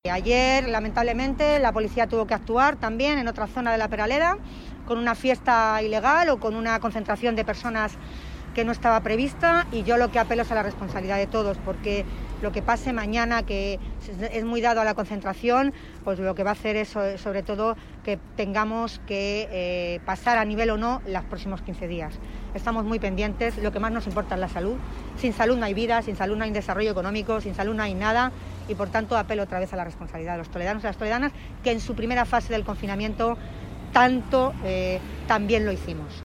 Audio Milagros Tolón:
milagros_tolon_responsabilidad.mp3